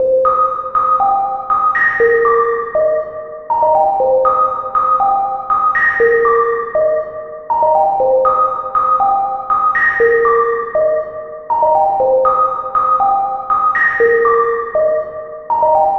beautiful-lead.wav